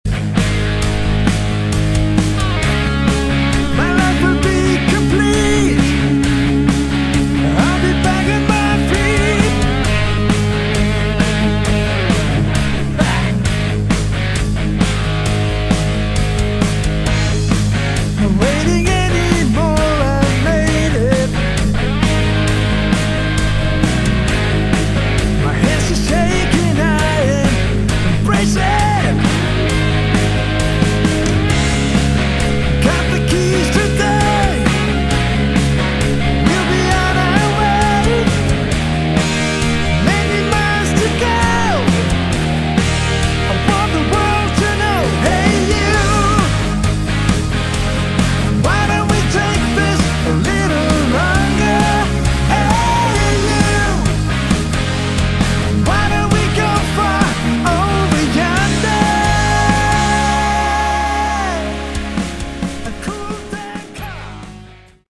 Category: Melodic Rock / AOR